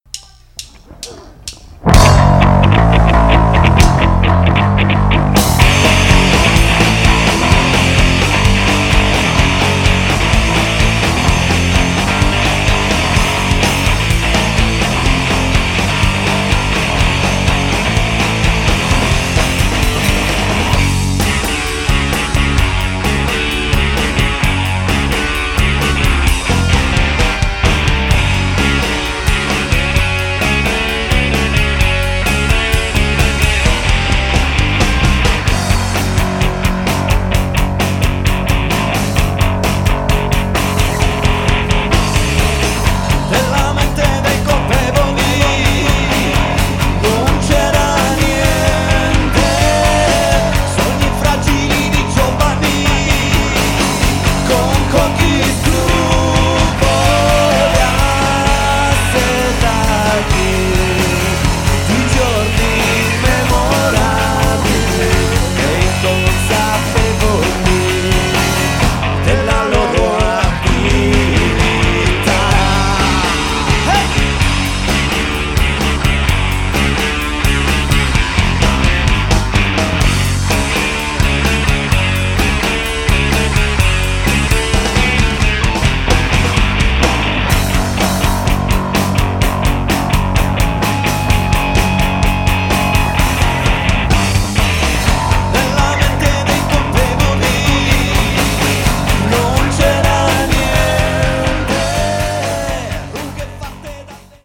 rock music
Genere: Rock.